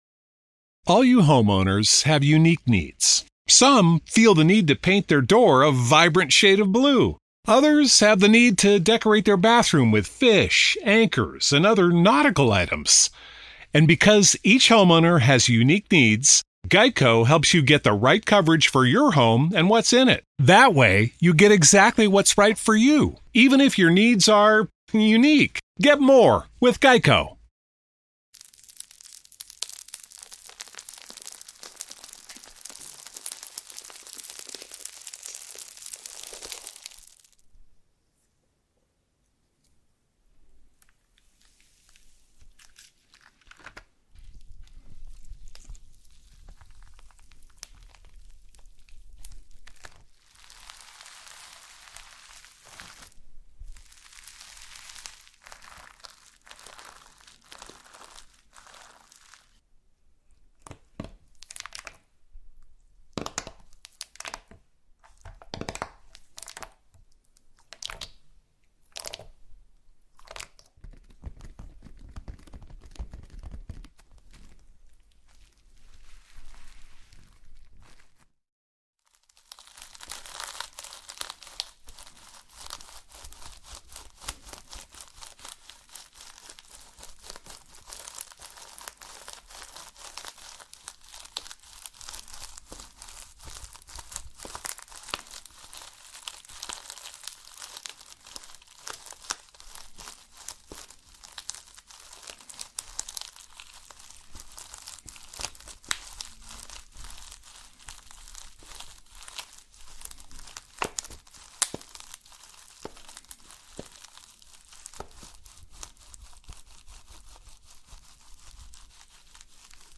ASMR SLIME No Talking Satisfying slime Sounds